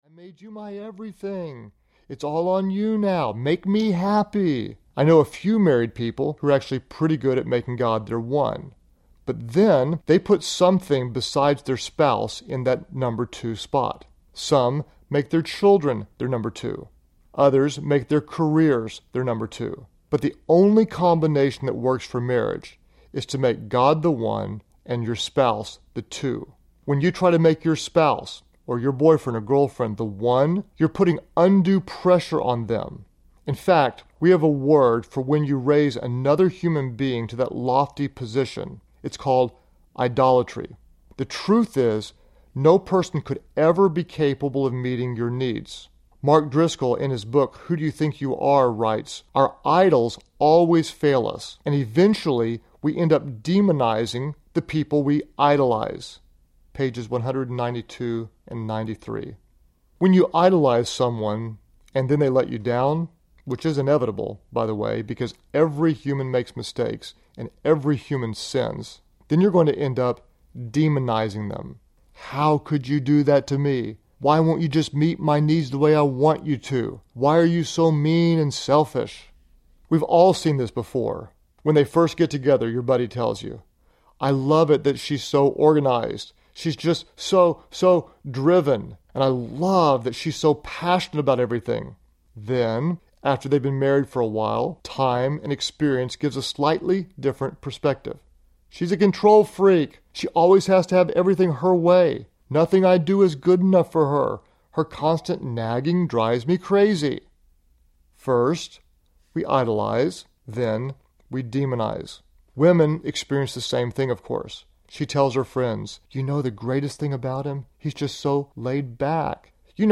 Narrator
3.6 Hrs. – Unabridged